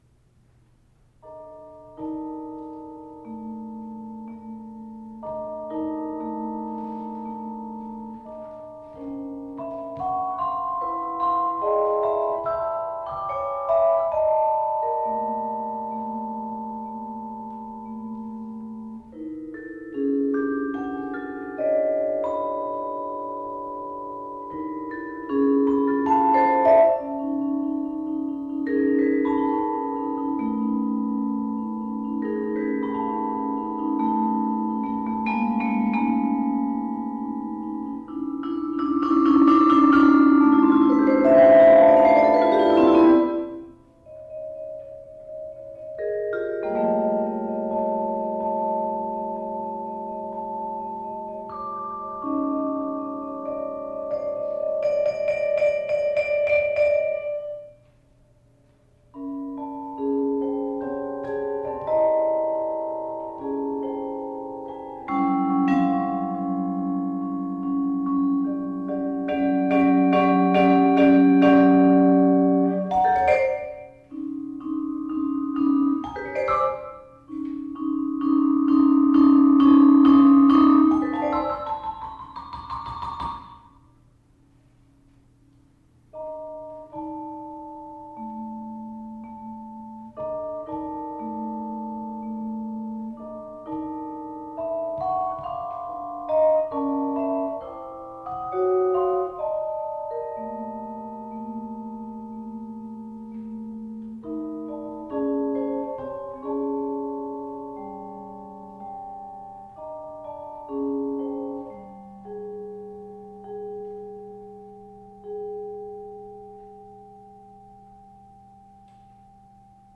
Genre: Vibraphone (4-mallet)